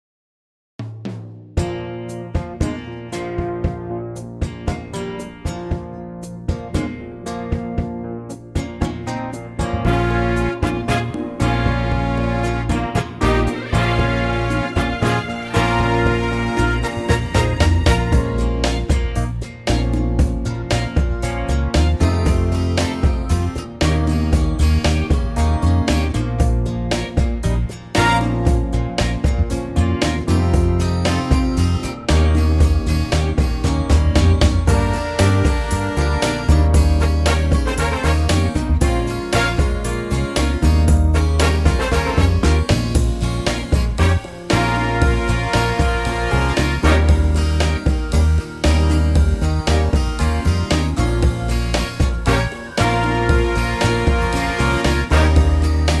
Suit higher male ranges.